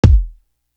Worst To Worst Kick.wav